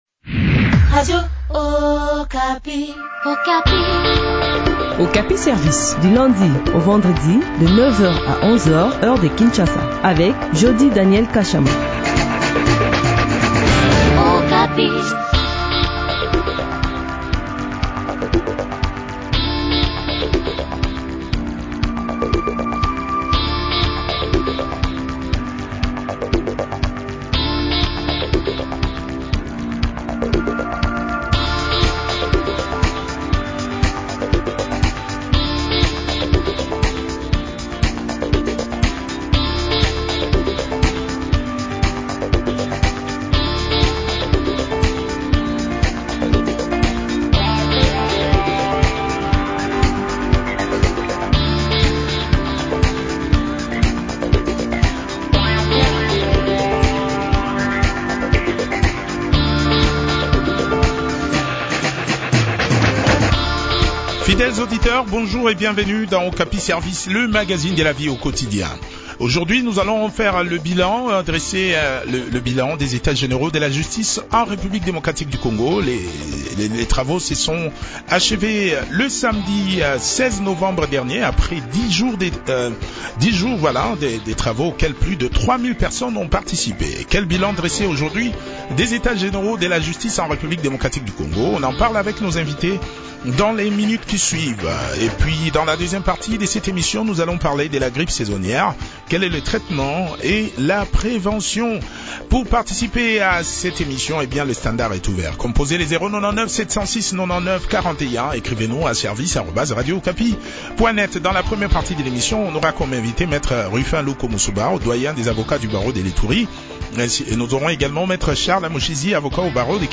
avocat au Barreau de Kinshasa/Matete a également participe à cette interview.